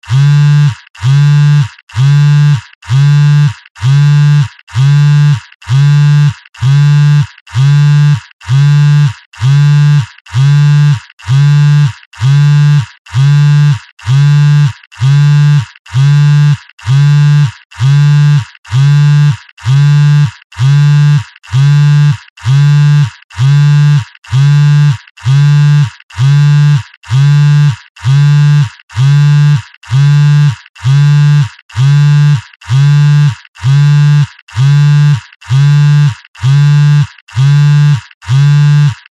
速い間隔でバイブレーションするガラケーバイブ音です。